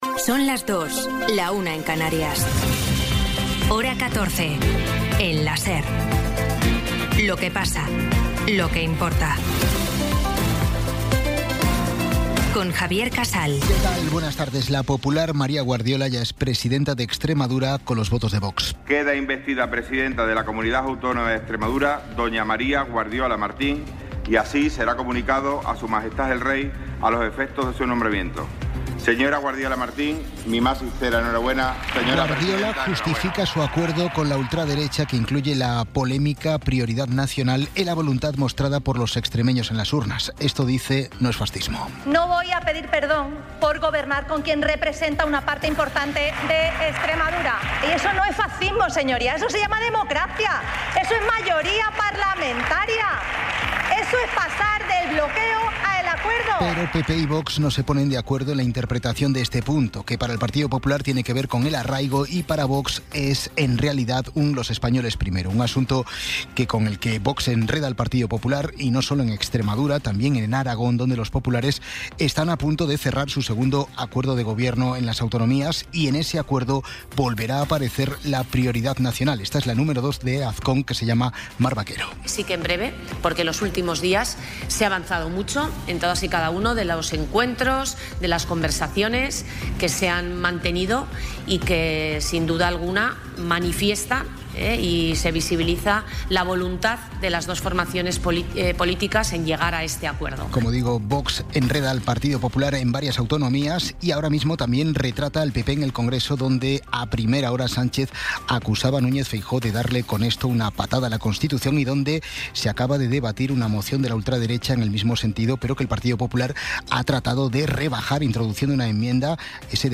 Resumen informativo con las noticias más destacadas del 22 de abril de 2026 a las dos de la tarde.